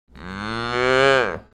دانلود صدای مزرعه 12 از ساعد نیوز با لینک مستقیم و کیفیت بالا
جلوه های صوتی
برچسب: دانلود آهنگ های افکت صوتی طبیعت و محیط دانلود آلبوم صدای مزرعه روستایی از افکت صوتی طبیعت و محیط